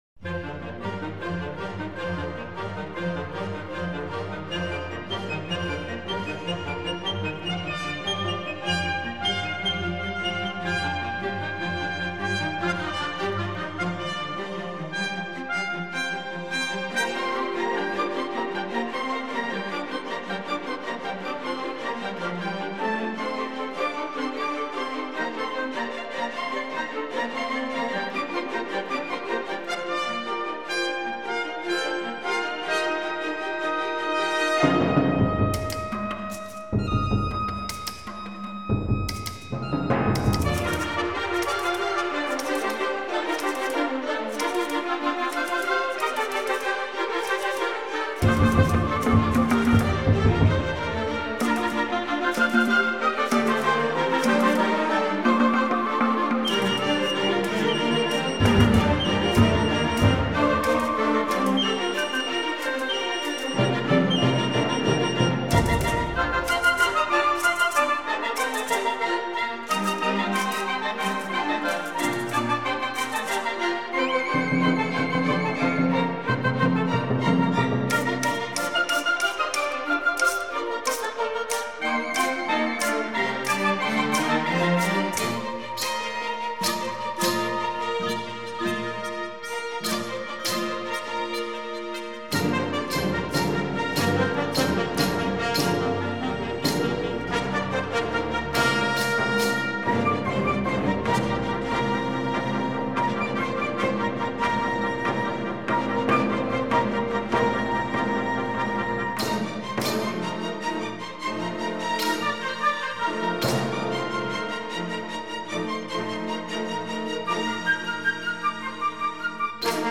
做于1935年，单乐章，采用古典奏鸣曲式，其中引用了印第安的民间音乐旋律。
民歌的旋律很丰富，许多民族打击乐器营造出喧腾欢快的气氛。